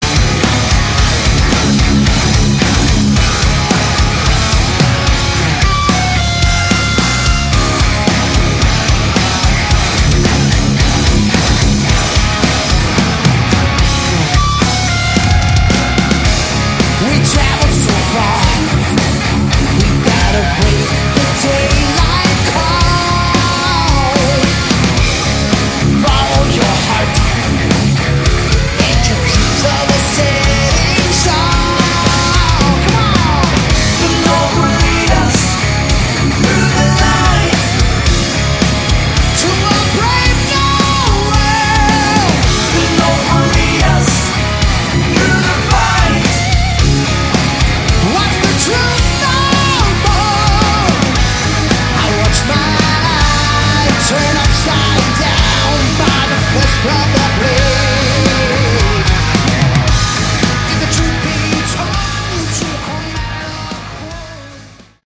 Category: Hard Rock
lead vocals
guitars
bass, vocals
keys, vocals
drums